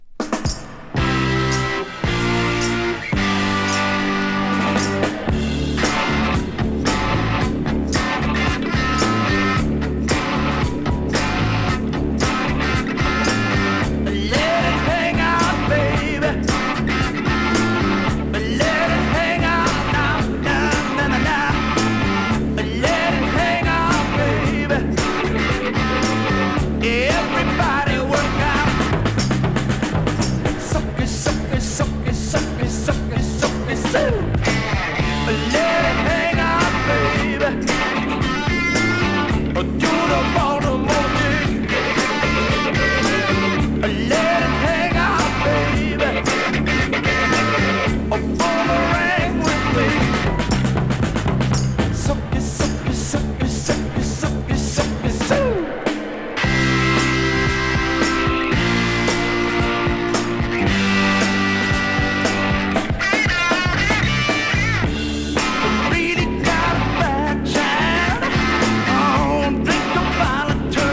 をハードロッキンに、
をネットリ・ブルージーにカヴァー。